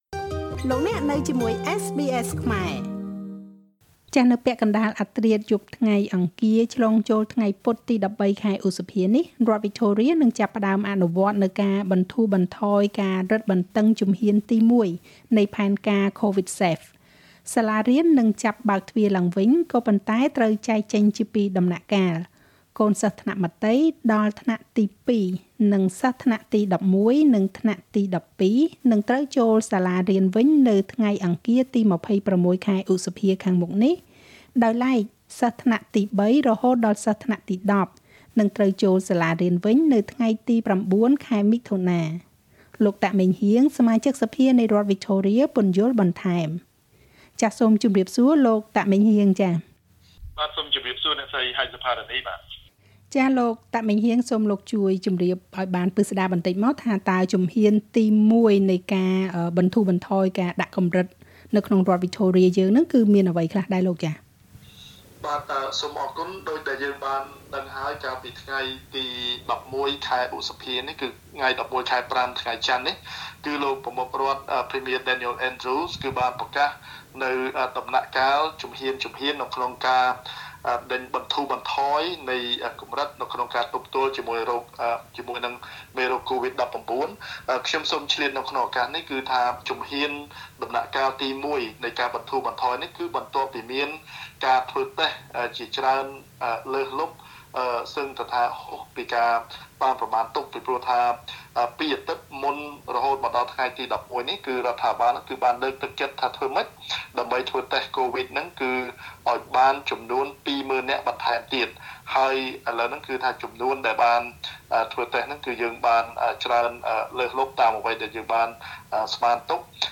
លោក តាក ម៉េងហ៊ាង សមាជិកសភានៃរដ្ឋវិចថូរៀ ពន្យល់បន្ថែម។